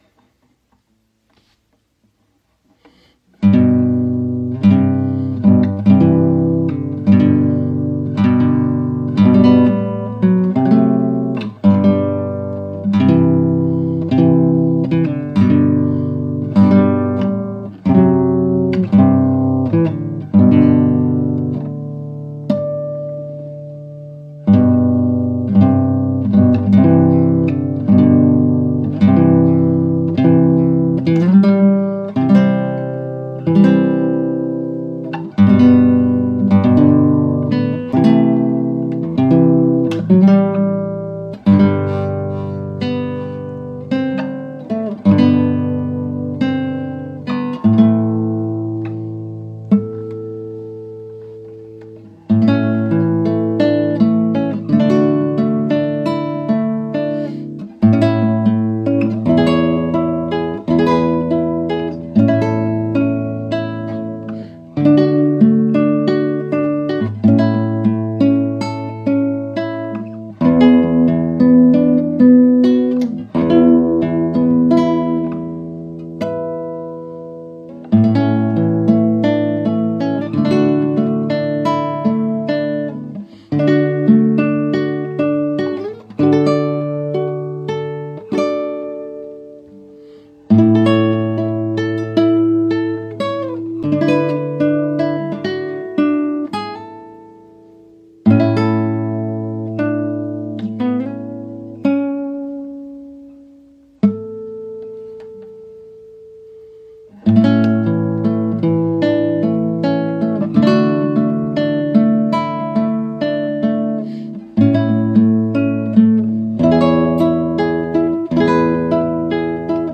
A classical guitar setting for "Come unto Jesus"
Voicing/Instrumentation: Guitar , Guitar Chords Available We also have other 27 arrangements of " Come unto Jesus ".
Recordings are done on my phone in the living room...often with some funny background noise!